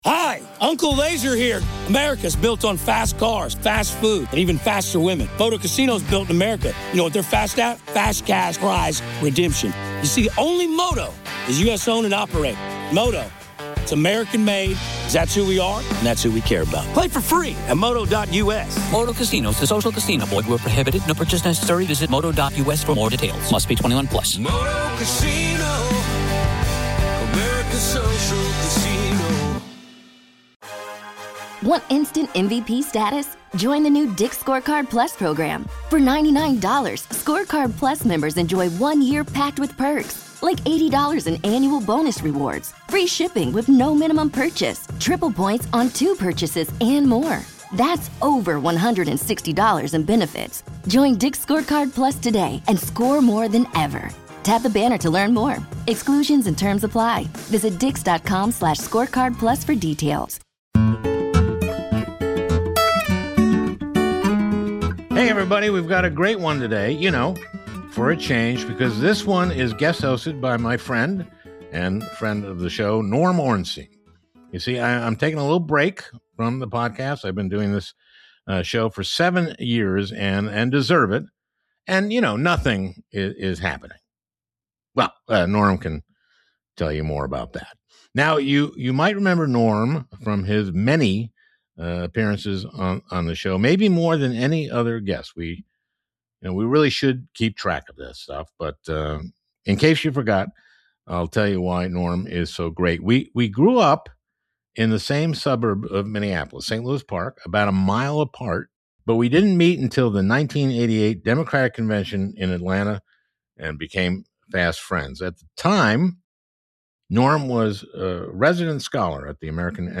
Guest Host Norm Ornstein welcomes Rep. Madeleine Dean (D-PA) to discuss legislation they're working on to curb the President's pardon power after the onslaught of blatantly corrupt pardons from the Trump administration.